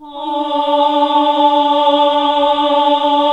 AAH D2 -L.wav